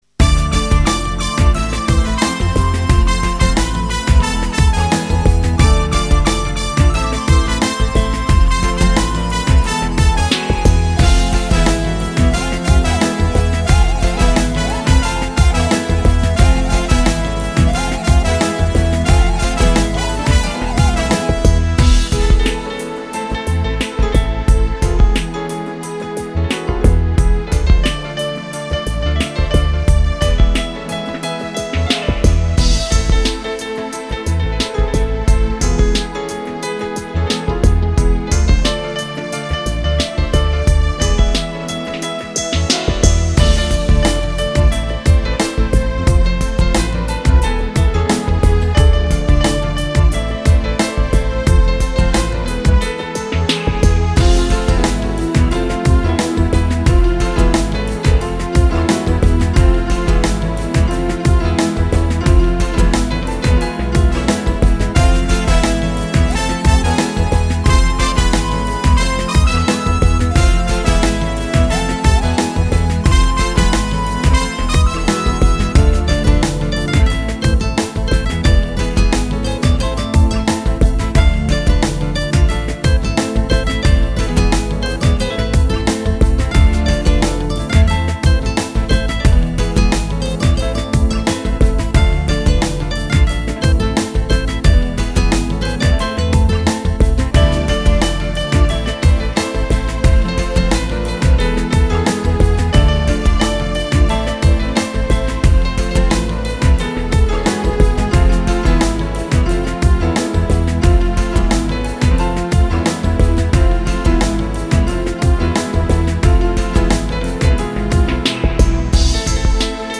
Notasız ve kulaktan çalmaya çalıştım gene..